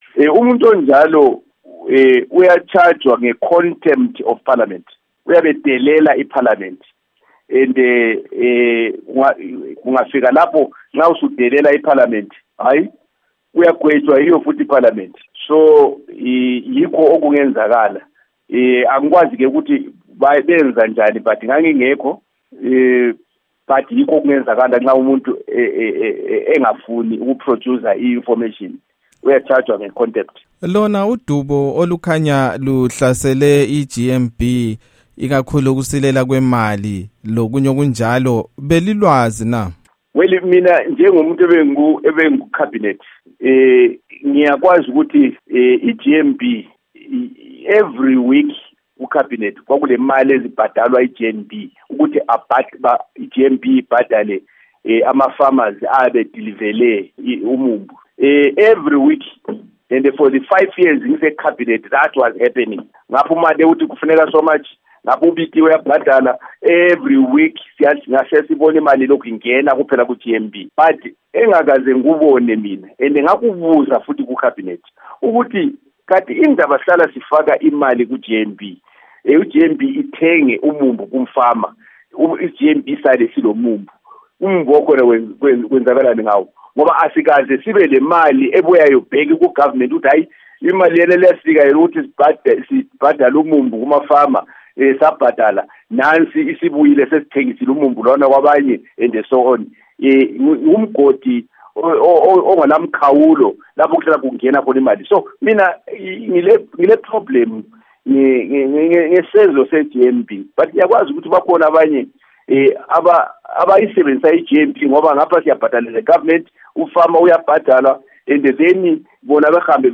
Ingxoxo loMnu. Sam Sipepa Nkomo